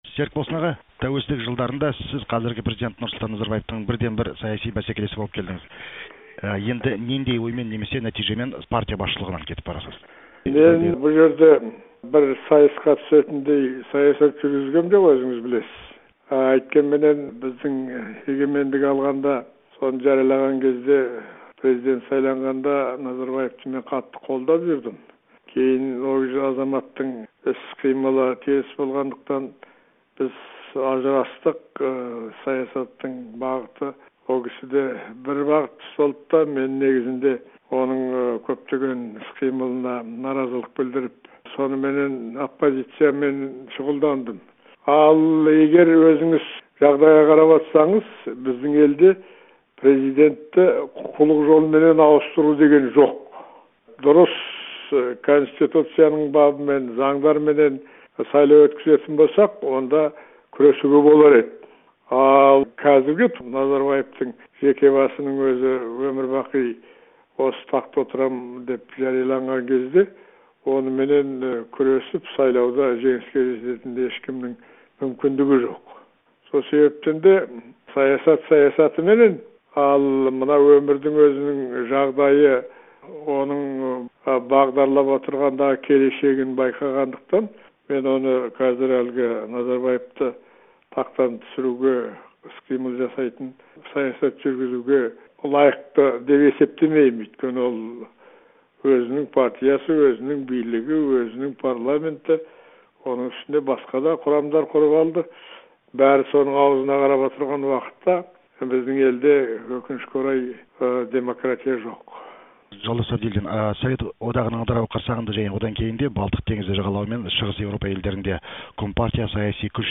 Серікболсын Әбділдинмен сұхбатты тыңдаңыз